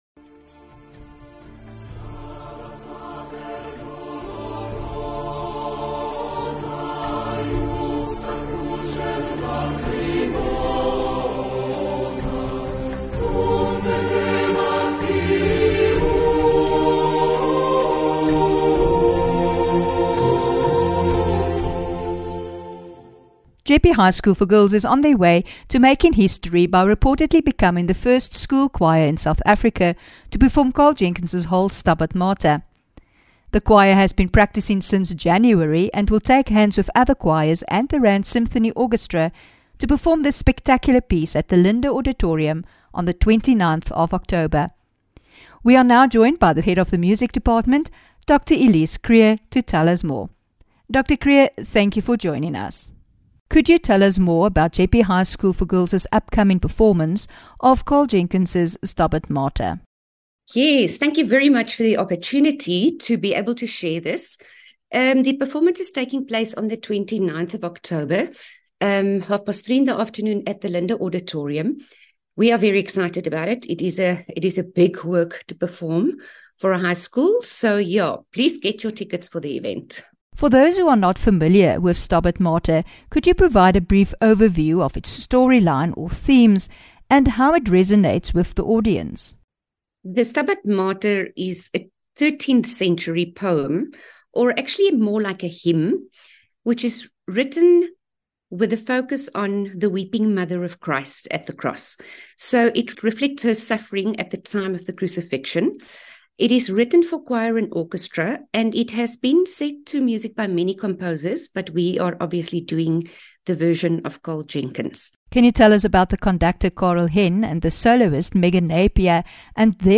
22 Oct INTERVIEW: JEPPE HIGH SCHOOL FOR GIRLS MAKES HISTORY WITH STABAT MATER PERFORMANCE